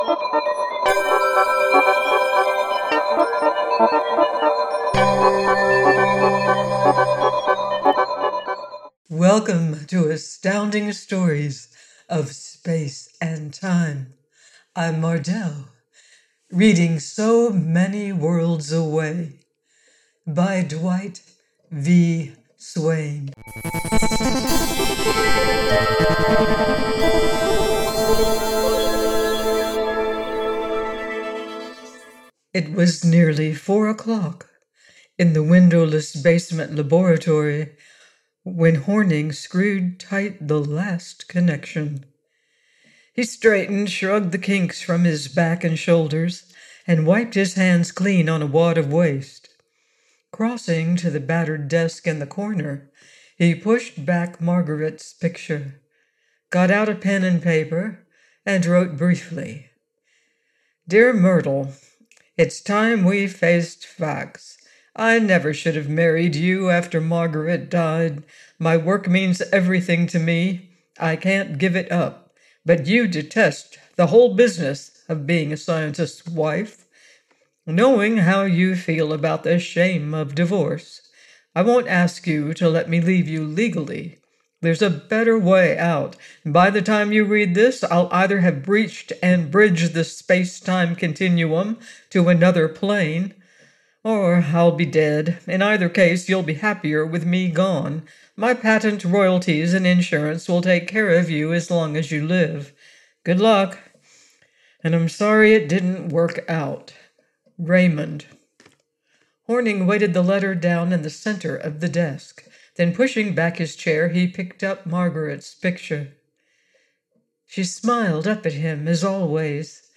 So Many Worlds Away by Dwight V. Swain - AUDIOBOOK